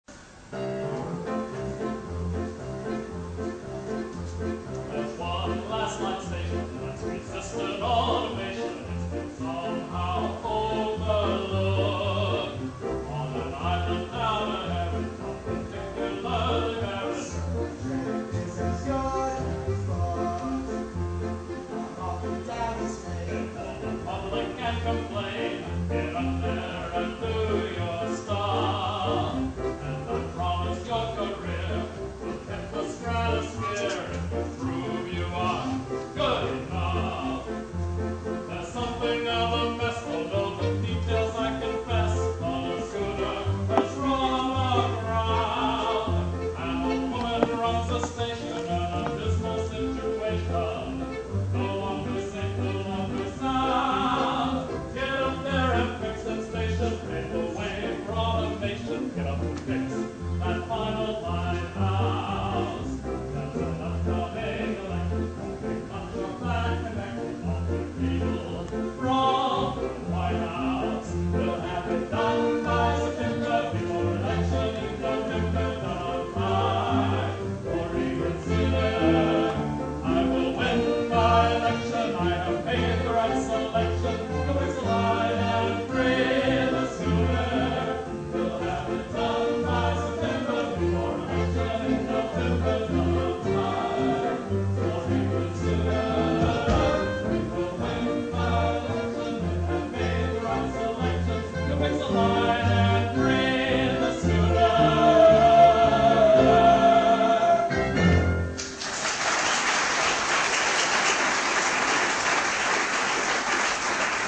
so it is not of very high quality.  The feeling is there, though, and the excitement of the night comes through.
PREMIER PERFORMANCE, WALDO THEATER, JULY, 2008